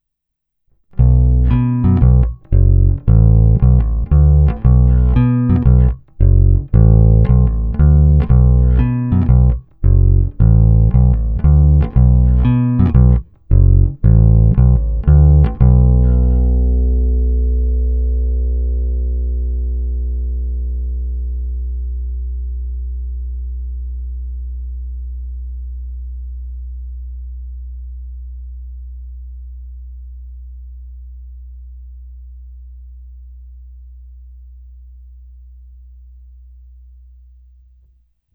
Zvuk je opravdu šedesátkový, středobasový, ne tak ostrý jako padesátkové nebo sedmdesátkové kousky, ale není ani zahuhlaný.
Není-li uvedeno jinak, následující nahrávky jsou provedeny rovnou do zvukové karty, jen normalizovány, jinak ponechány bez úprav.
Hráno vždy s plně otevřenou tónovou clonou.
Hra u krku